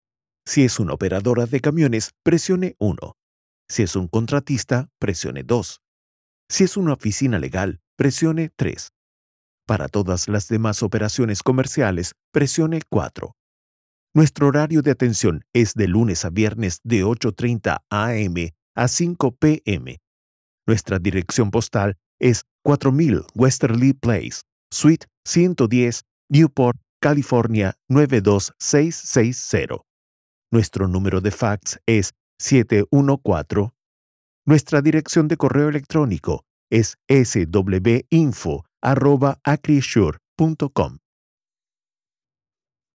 Kommerziell, Junge, Cool, Vielseitig, Corporate
Telefonie